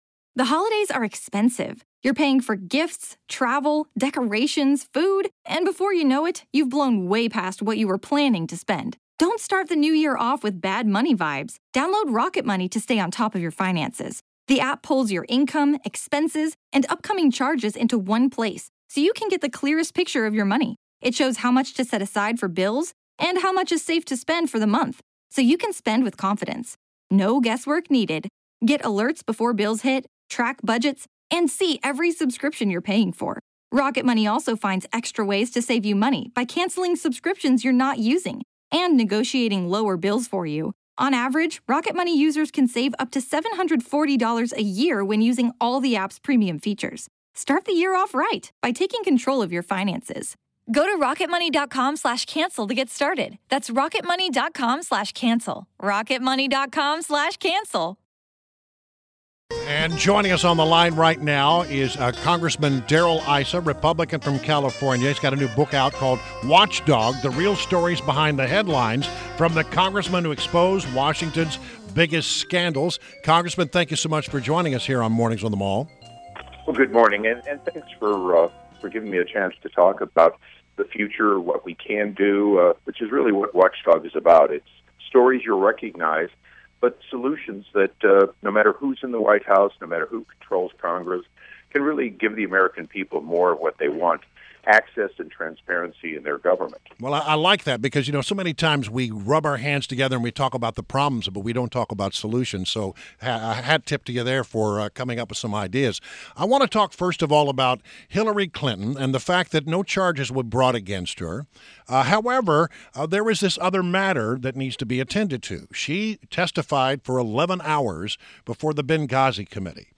WMAL Interview - Rep. Darrell Issa - 07.14.16